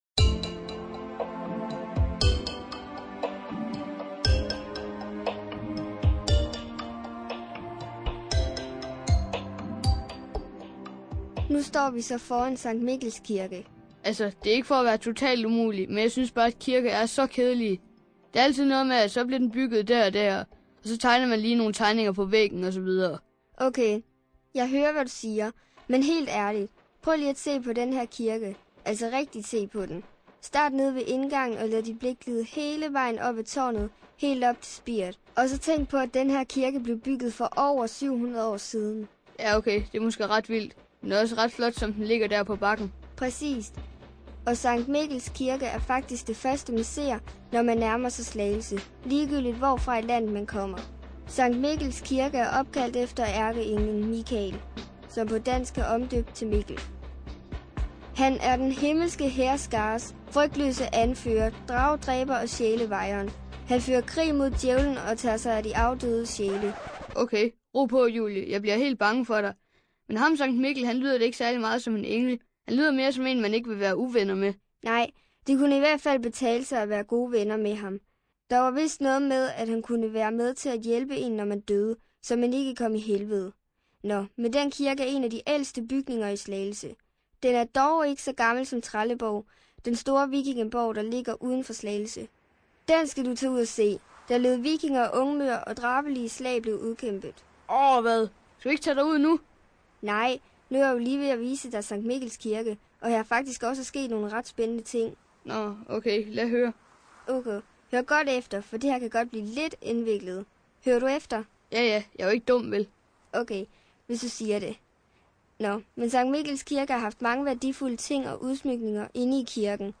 PodWalk (Danmark)